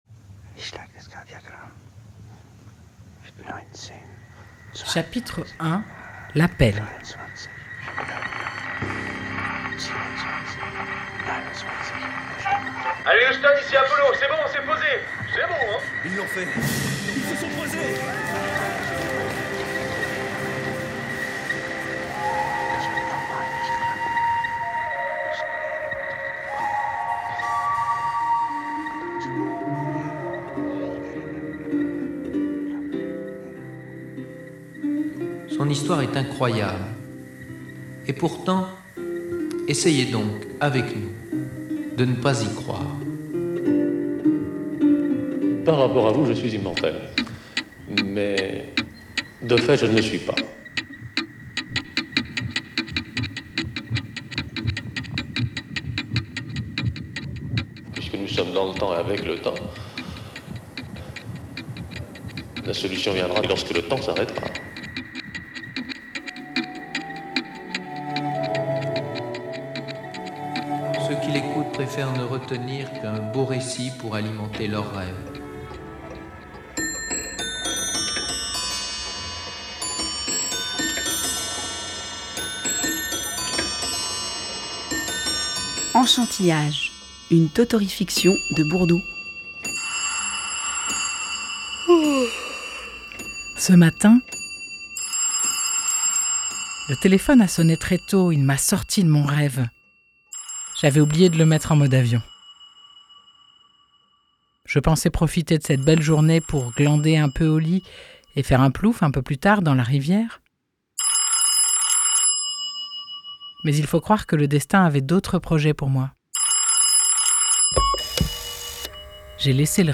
Une enquête sonore qui a invité enfants et adultes, habitants de Bourdeaux, à puiser ensemble dans cette ressource inépuisable qu’est l’imagination.